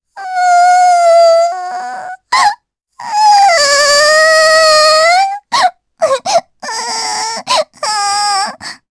Luna-Vox_Sad_jp.wav